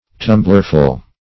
Search Result for " tumblerful" : The Collaborative International Dictionary of English v.0.48: Tumblerful \Tum"bler*ful\, n.; pl.
tumblerful.mp3